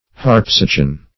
Harpsichon \Harp"si*chon\ (h[aum]rp"s[i^]*k[o^]n)